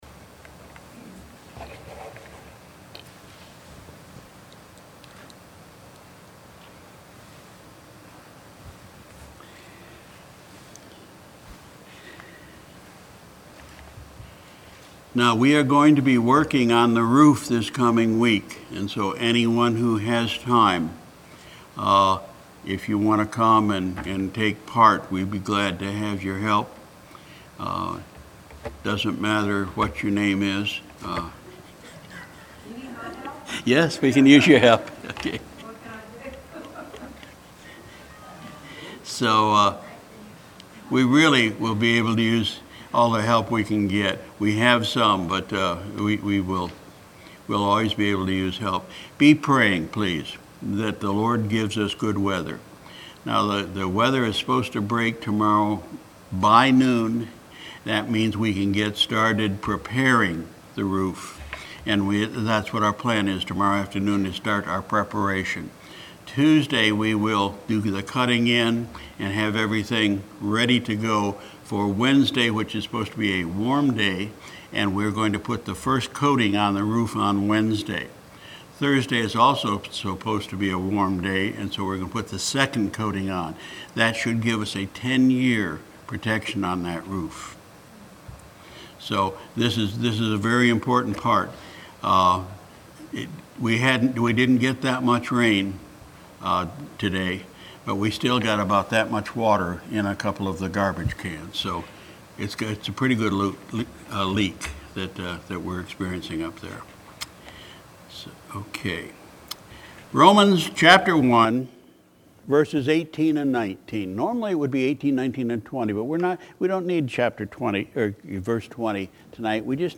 Sunday, March 25, 2018 – Evening Service